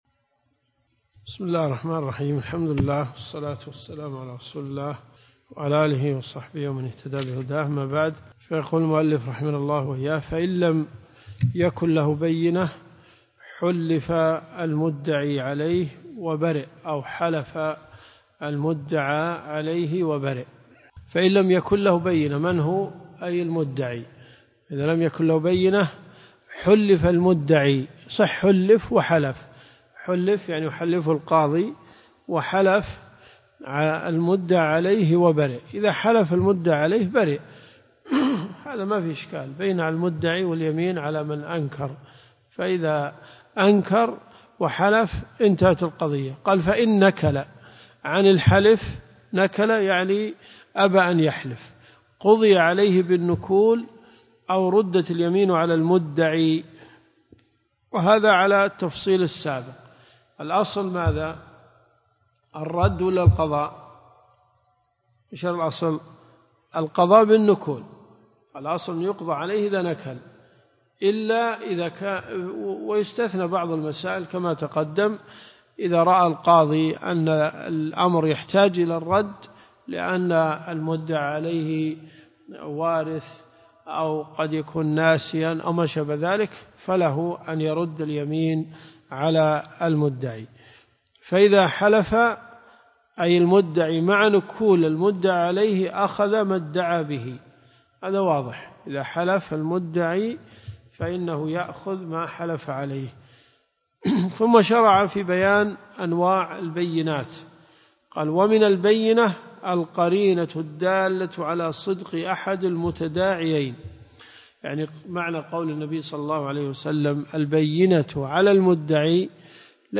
دروس صوتيه